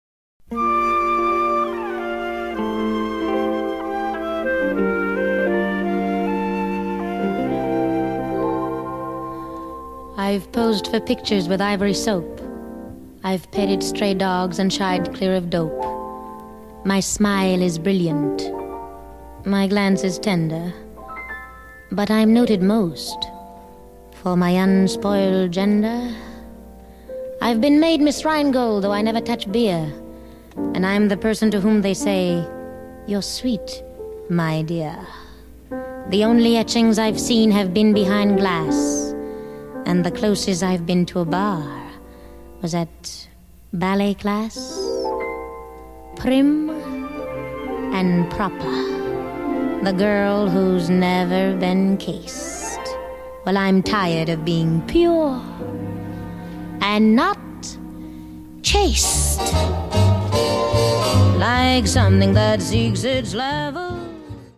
vinyl audiophile pressing
intimate and supple rendering